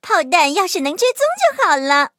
M2中坦开火语音1.OGG